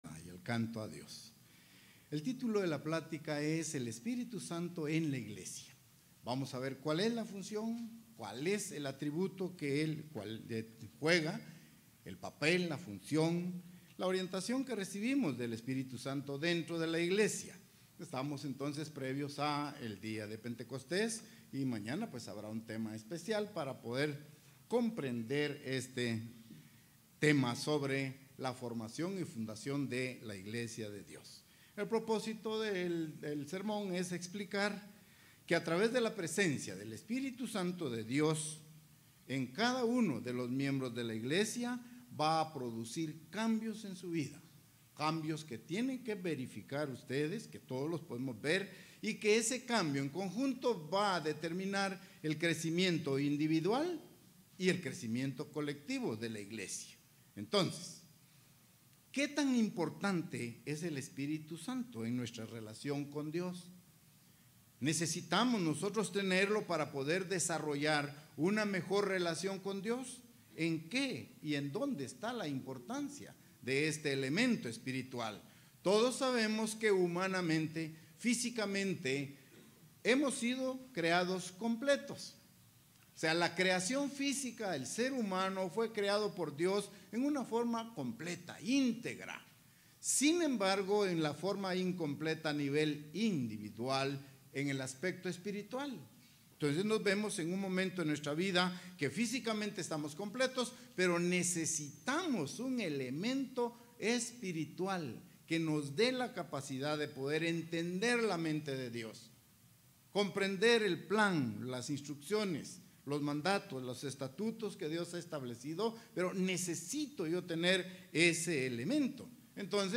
¿Cuál es la función y la naturaleza del Espíritu Santo? ¿Qué diferencia hace en la vida del cristiano y en la vida de la Iglesia de Dios? Mensaje entregado el 8 de junio de 2019.